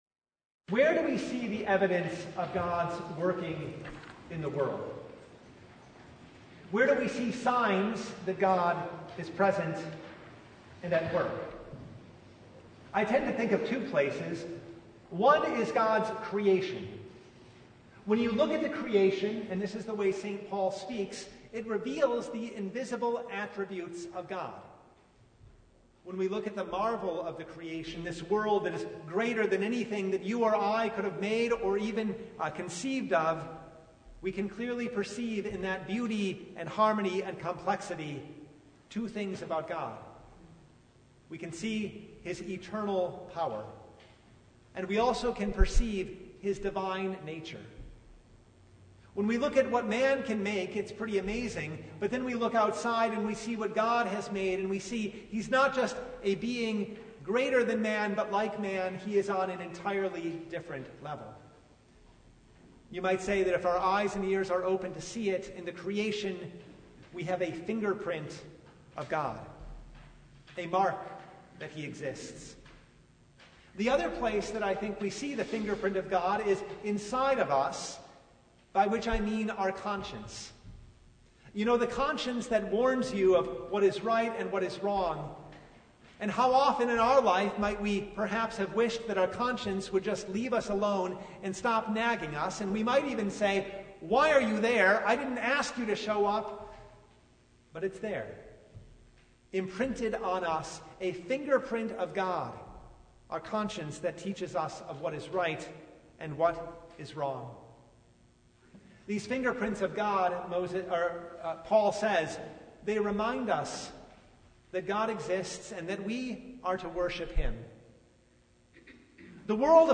Exodus 8:1-32 Service Type: Lent Midweek Noon Topics: Sermon Only